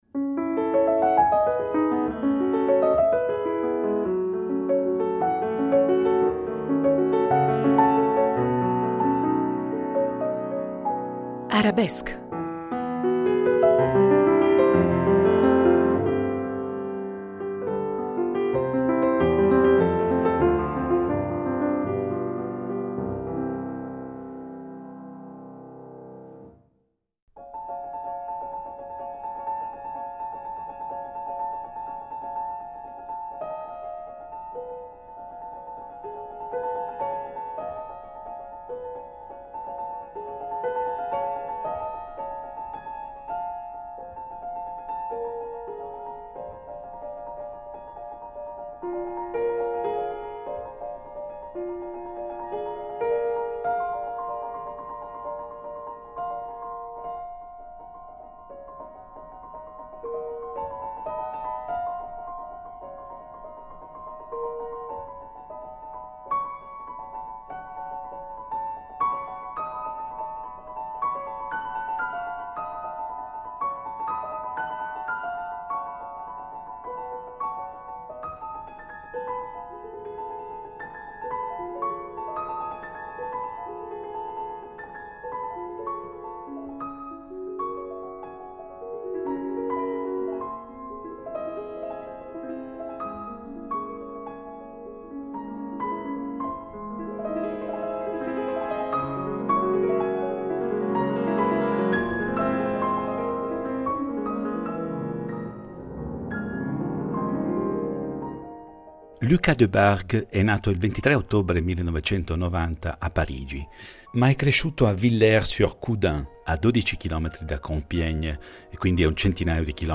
Ci troviamo all’esterno della Chiesa di Verbier, dove s’è appena concluso un concerto con il pianista francese Lucas Debargue , e con la prima esecuzione svizzera del Trio composto dal pianista stesso.